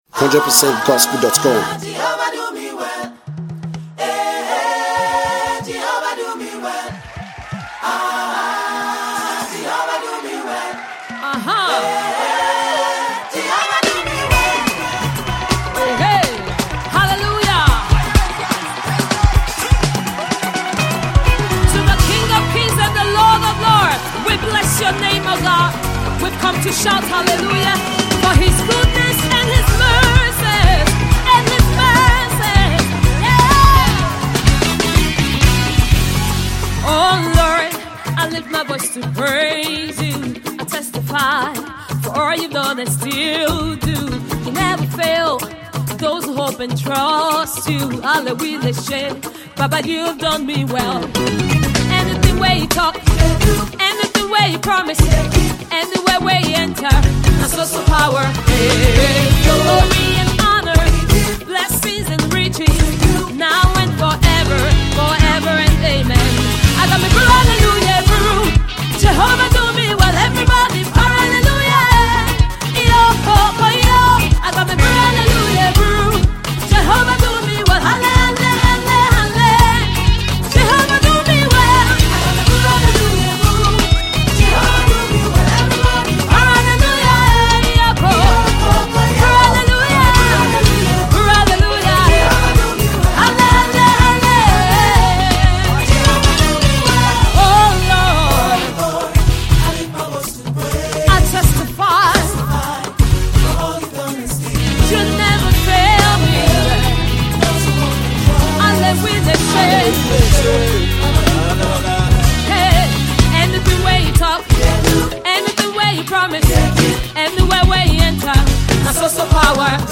Gospel music
African Praise
The song is Afrocentric with west African percussion.